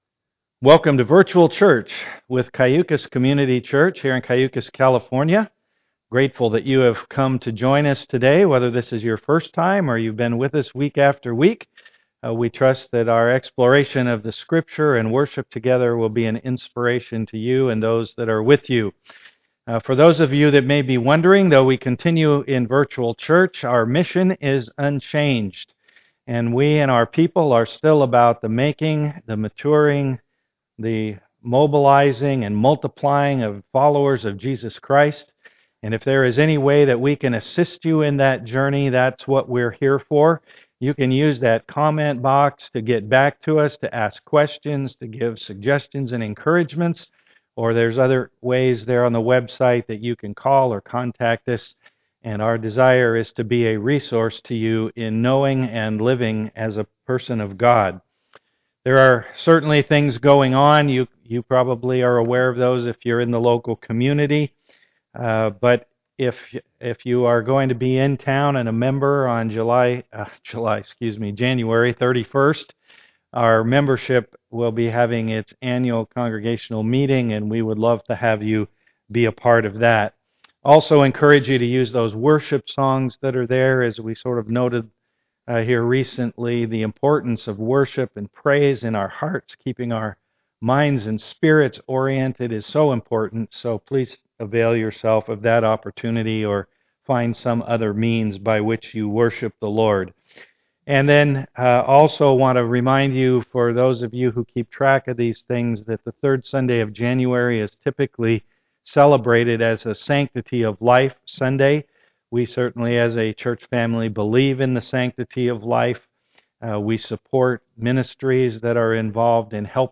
January 17, 2021 God’s Top 10, pt 2 Series: Walking the (COVID-19) Wilderness With Moses Passage: Exodus 20:1-21 Service Type: am worship Click on the links below to enjoy a time of worship prior to listening to the message.